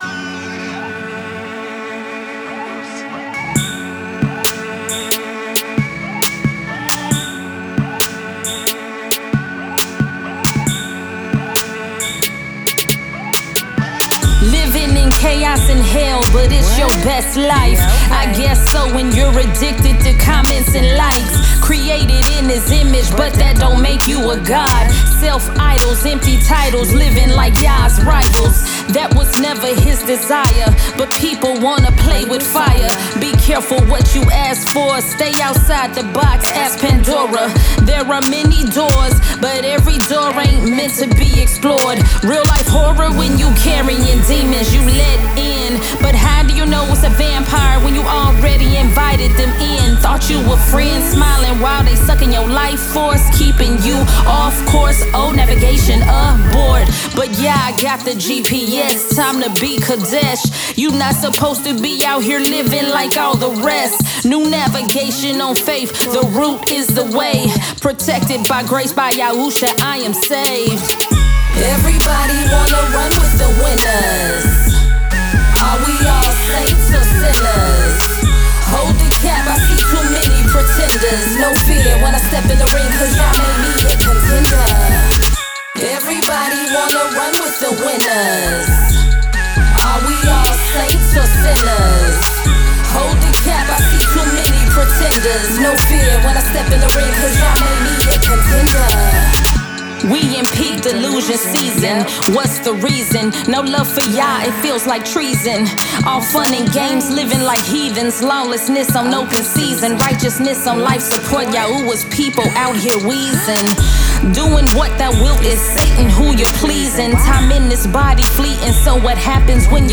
Driven by gritty 808s and cinematic hip-hop production